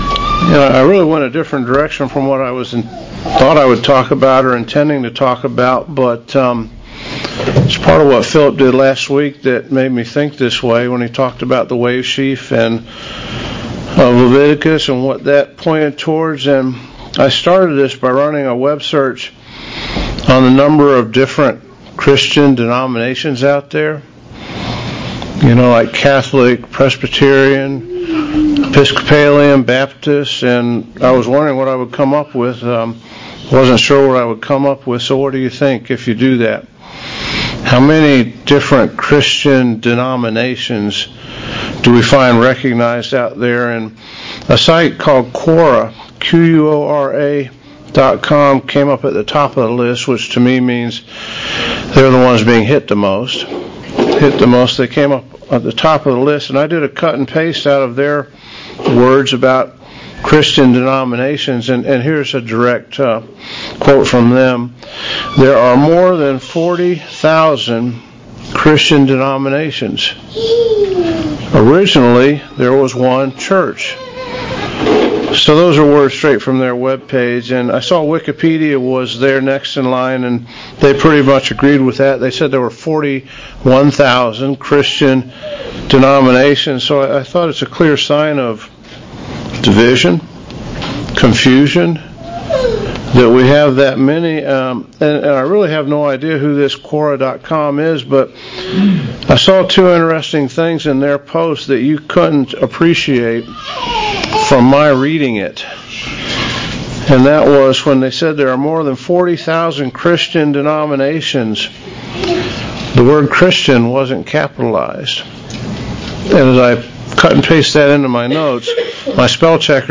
This sermon discusses God's plan and how God is dealing with mankind, and those whom He has called to be Firstfruits now.
Given in Buford, GA